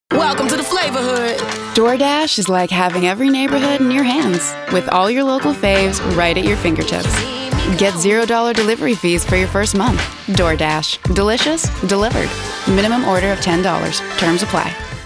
Publicités - ANG